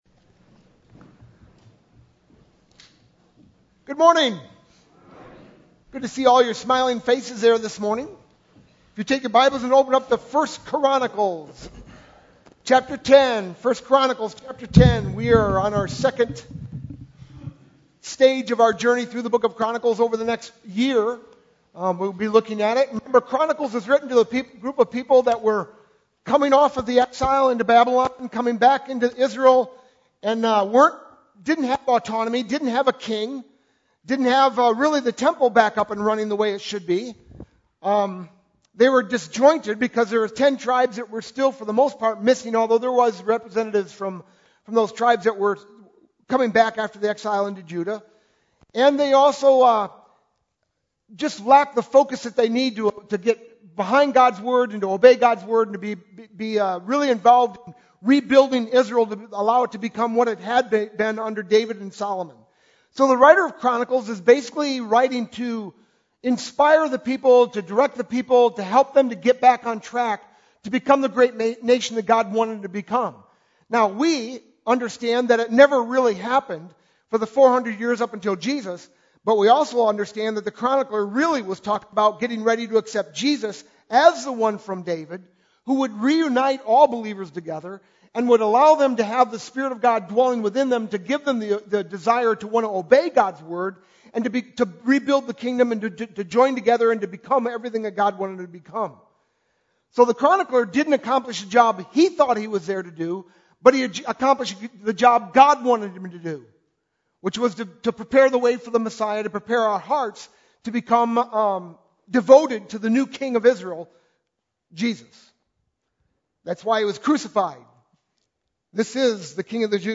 sermon-4-22-12.mp3